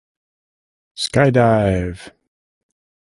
Pronúnciase como (IPA) /ˈskaɪˌdaɪv/